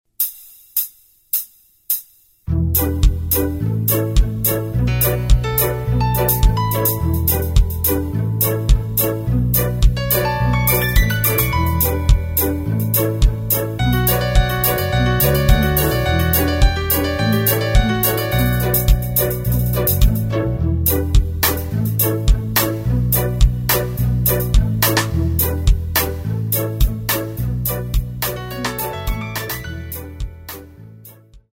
No solos.
Key of E minor
Backing track only.